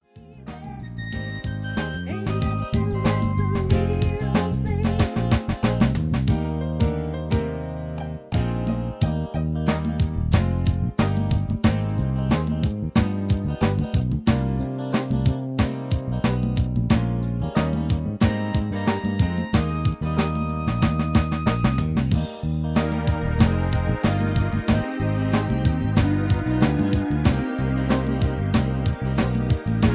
karaoke mp3s , backing tracks